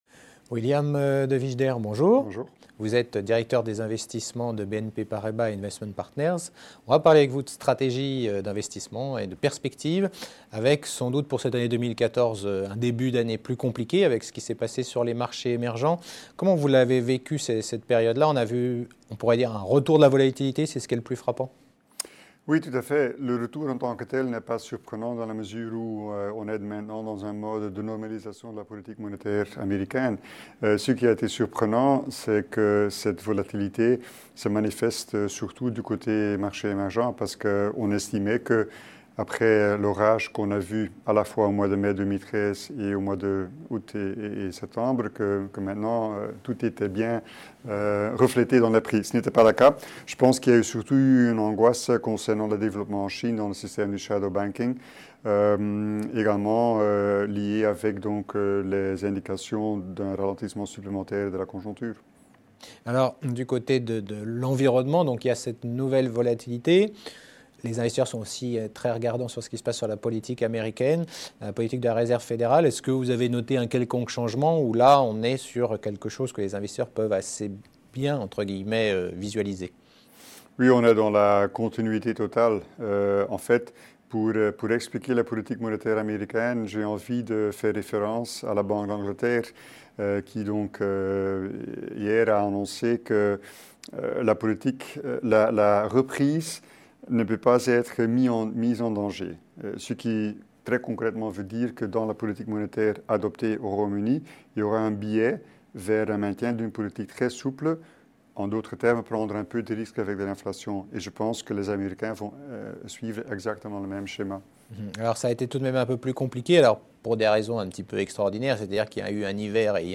Tv Bourse : Interview